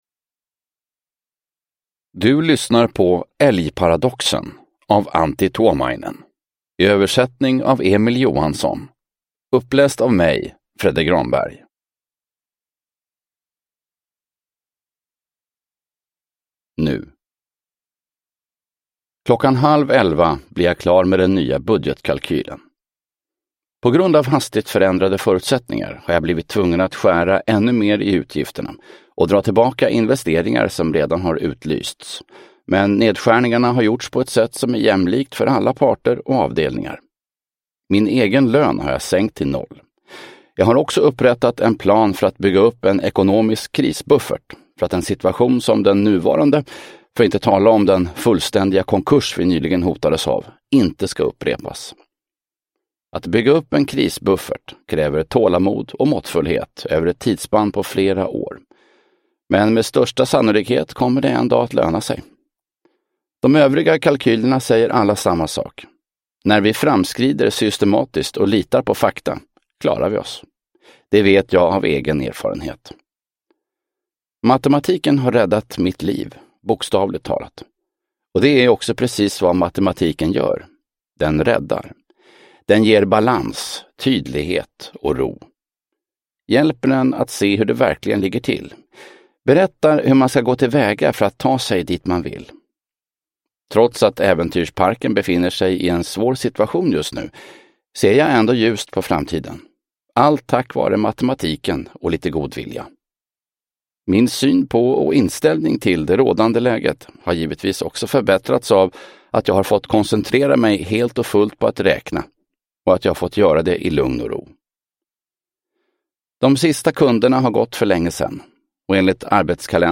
Älgparadoxen – Ljudbok – Laddas ner